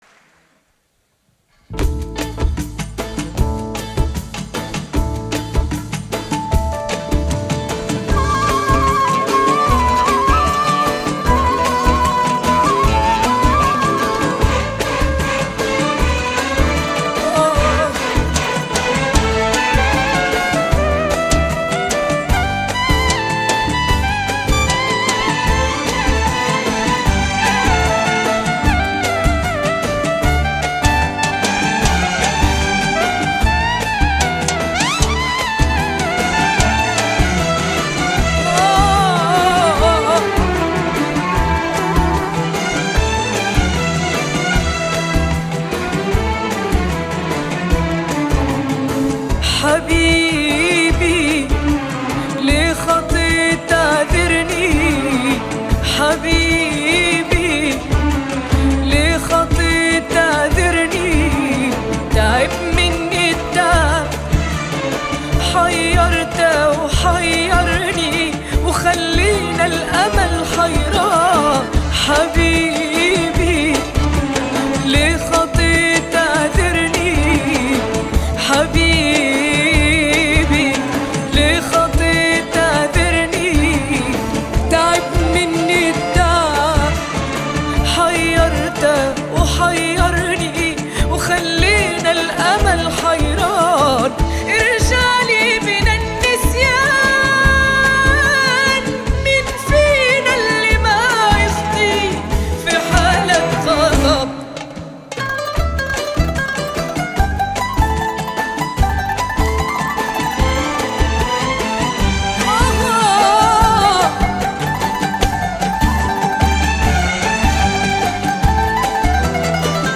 دار الأوبرا 2025